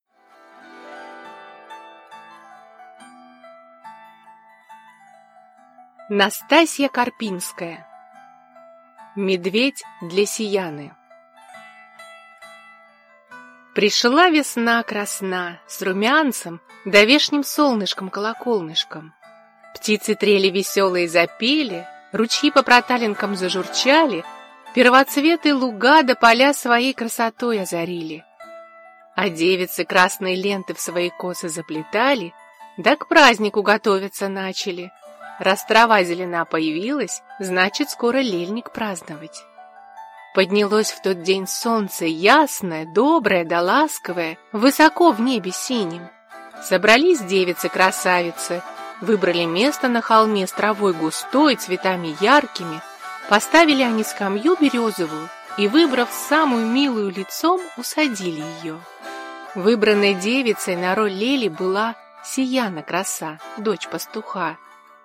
Аудиокнига Медведь для Сияны | Библиотека аудиокниг